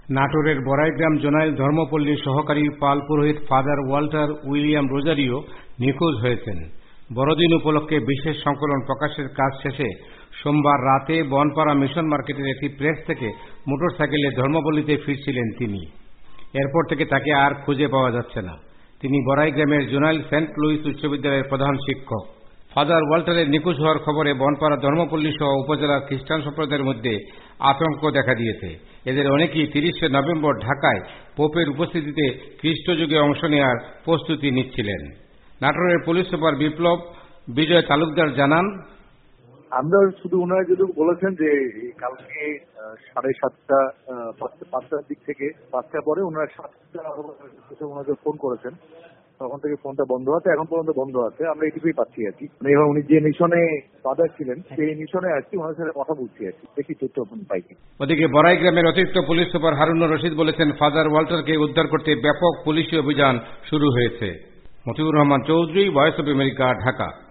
রিপোর্ট